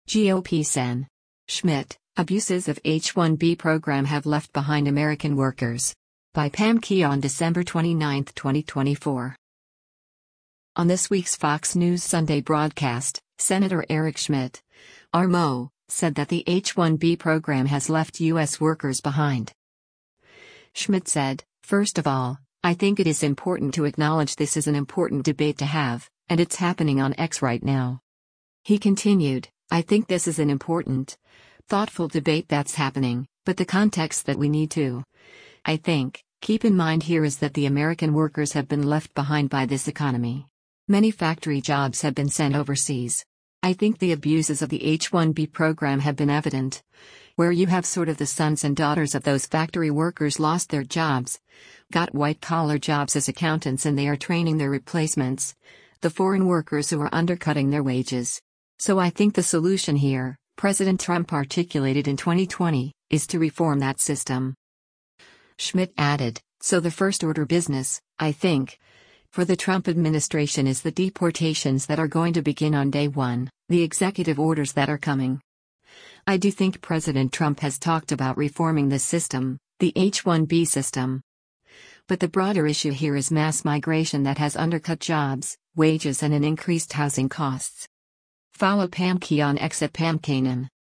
On this week’s Fox News Sunday broadcast, Sen. Eric Schmitt (R-MO) said that the H-1B program has left U.S. workers “behind.”